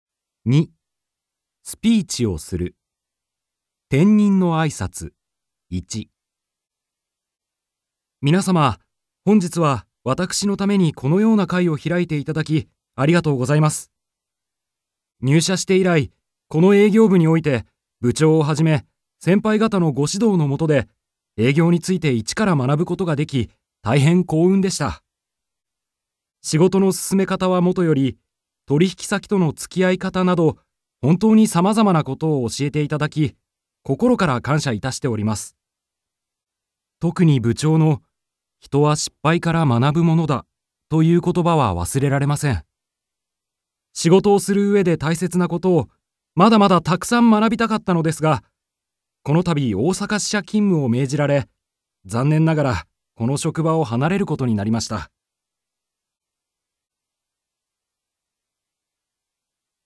改まった形で思い出話などをして、お別れのスピーチができる。